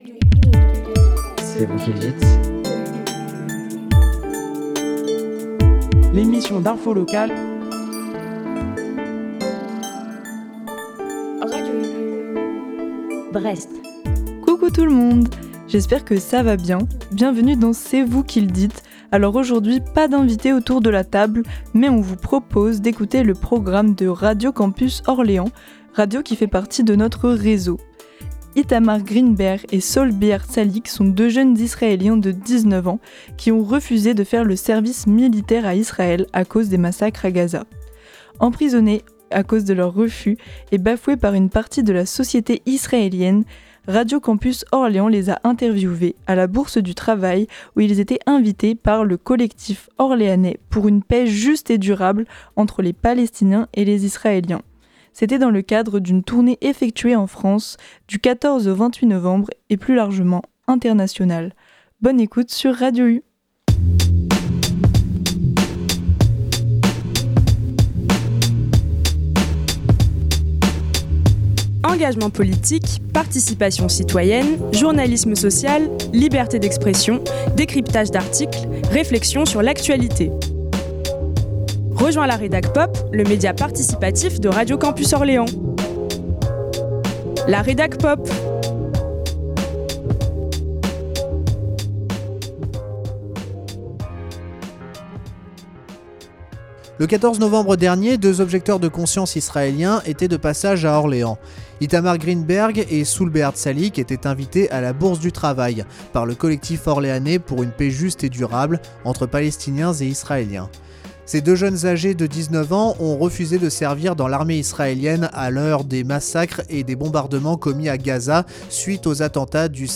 Aujourd'hui, pas d'invités autour de la table (et oui ça arrive).